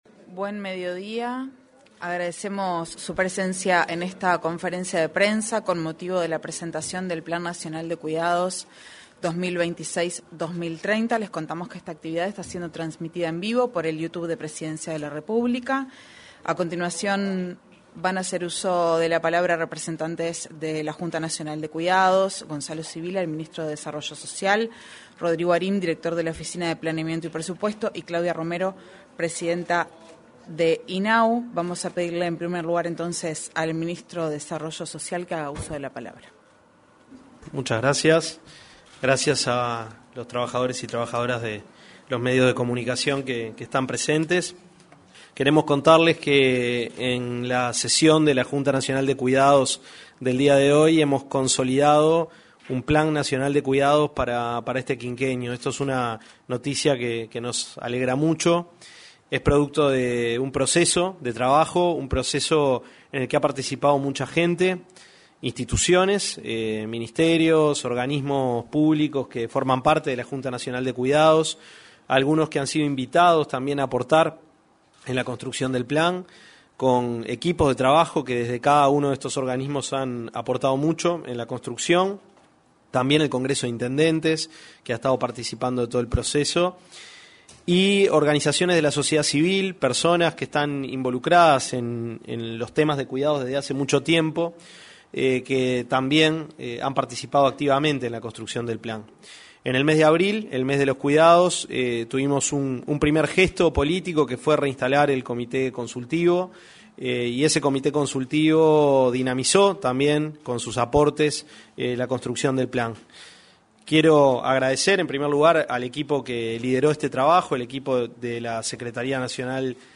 Presentación del Plan Nacional de Cuidados 28/10/2025 Compartir Facebook X Copiar enlace WhatsApp LinkedIn Se realizó, en la sala de prensa de la Torre Ejecutiva, la presentación del Plan Nacional de Cuidados. En la oportunidad, se expresaron el ministro de Desarrollo Social, Gonzalo Civila; la presidenta del Instituto del Niño y el Adolescente del Uruguay, Claudia Romero, y el director de la Oficina de Planeamiento y Presupuesto, Rodrigo Arim.